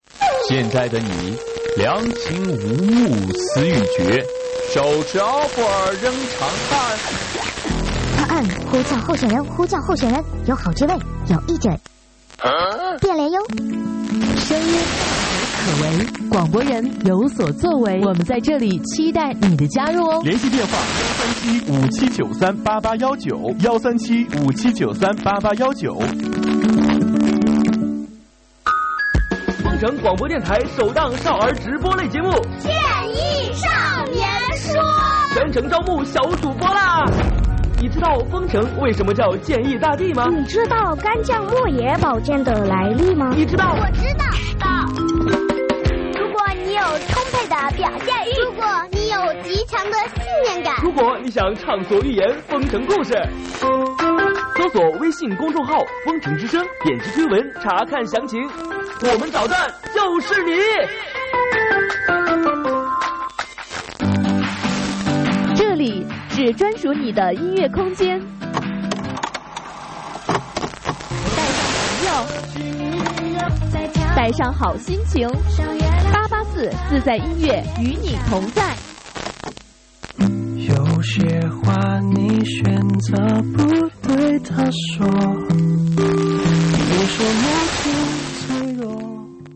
日本广岛历年接收fm88.4